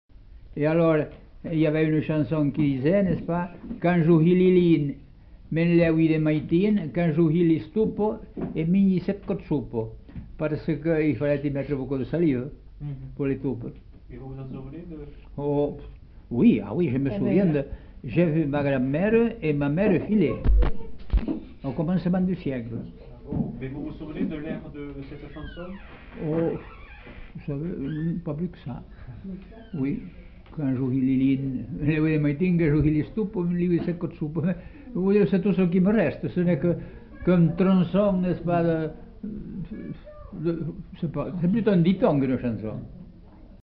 Lieu : Montaut
Genre : forme brève
Classification : proverbe-dicton
Ecouter-voir : archives sonores en ligne